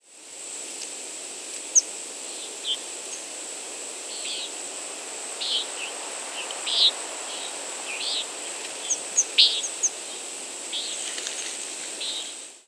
Pine Warbler diurnal flight calls
Diurnal calling sequences: